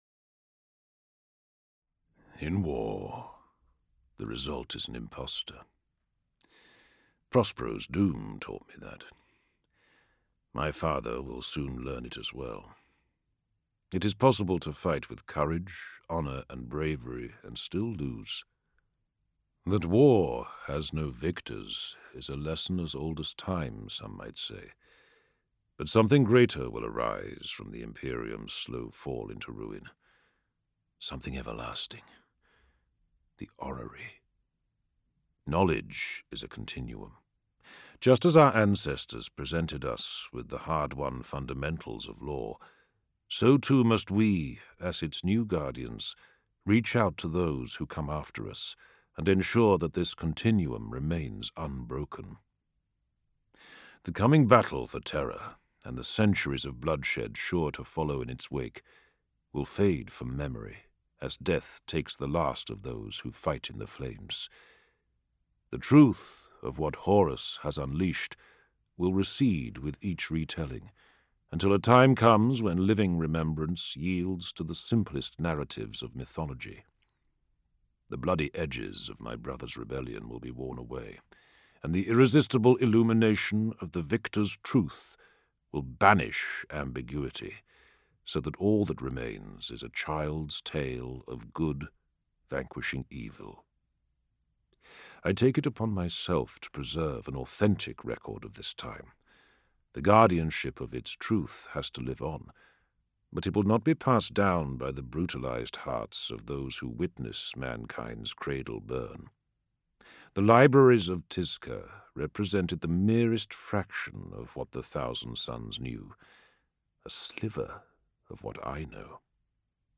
Games/MothTrove/Black Library/Horus Heresy/Audiobooks/The Complete Main Series/HH 41-50/44